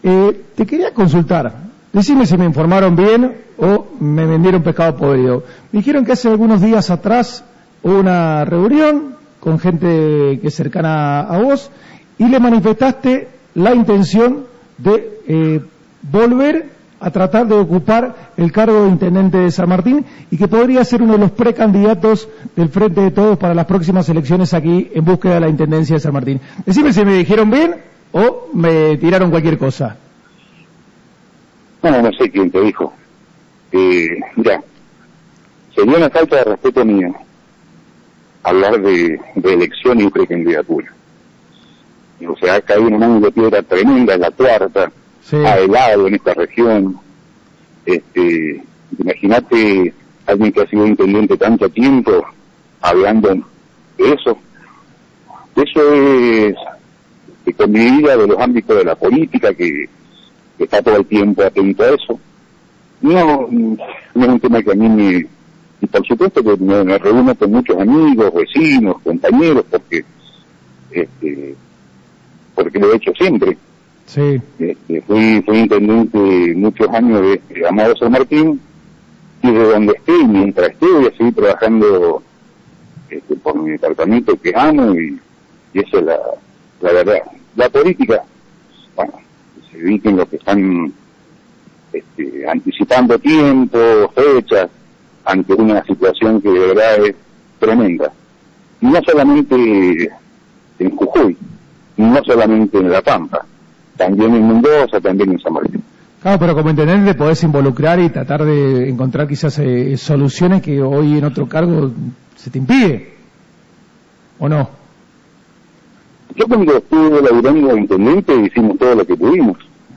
En una segunda parte de la entrevista realizada a Jorge Omar Giménez, se abordaron temas referidos a la política local y quedaron algunos títulos interesantes.
Aquí el resumen del audio de la nota realizada en FM 103.5 Gral. San Martin: